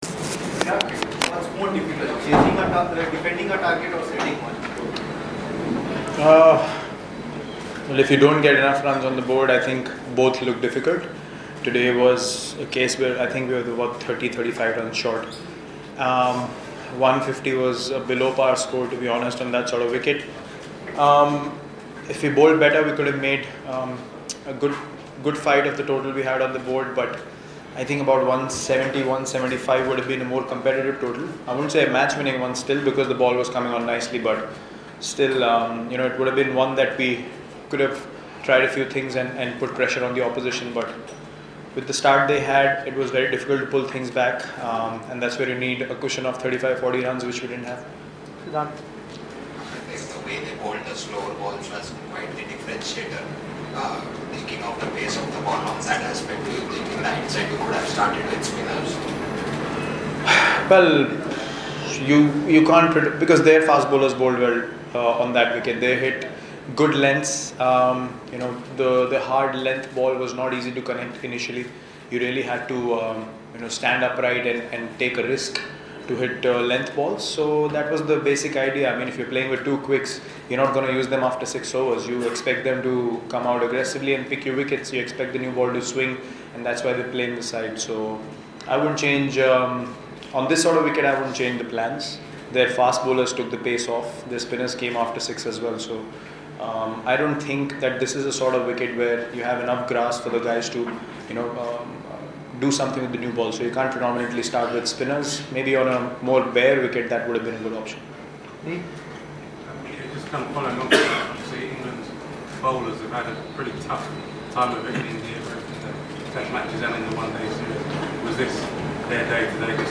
LISTEN: Skipper Virat Kohli Speaks on Kanpur T20I Defeat